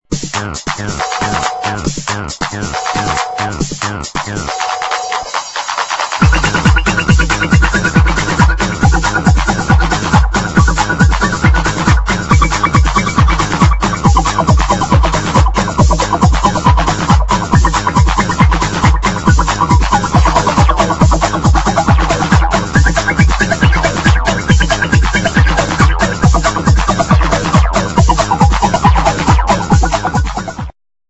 2000 nervous fast instr.